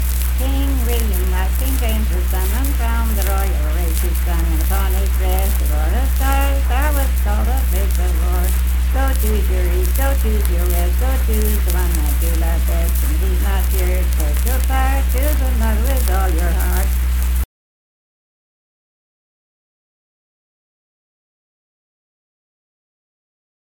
Unaccompanied vocal music performance
Dance, Game, and Party Songs
Voice (sung)